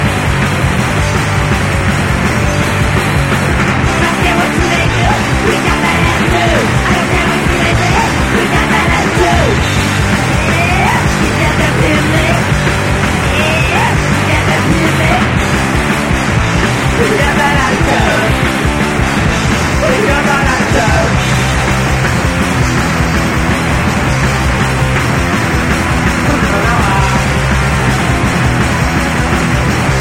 no âmago do movimento punk da cidade